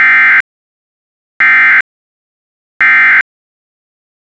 Open Download EOM Burst